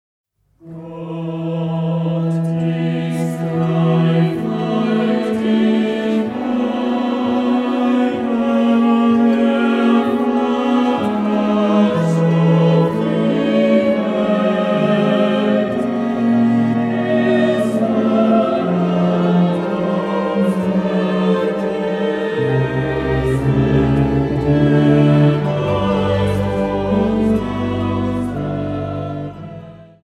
Harfe
Violine 1
Viola
Violoncello